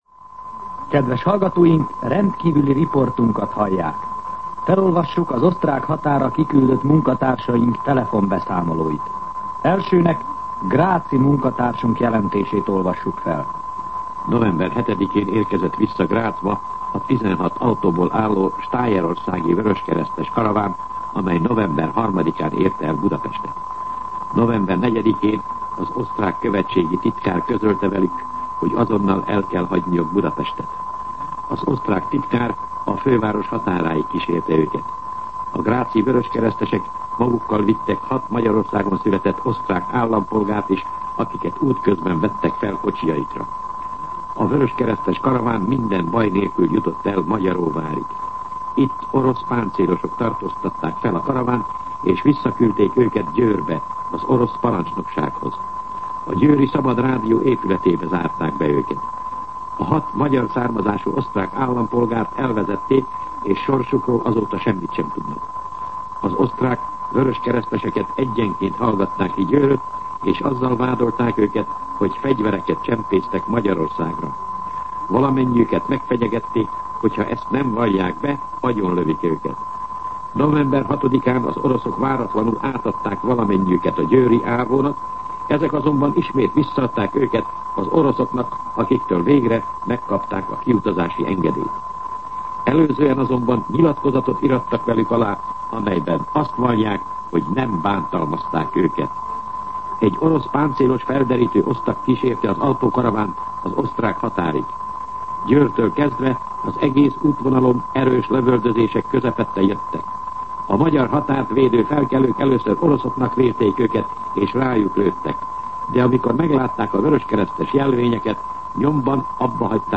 MűsorkategóriaTudósítás